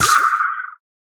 Minecraft Version Minecraft Version snapshot Latest Release | Latest Snapshot snapshot / assets / minecraft / sounds / mob / pufferfish / death2.ogg Compare With Compare With Latest Release | Latest Snapshot